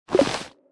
pick_up_1.wav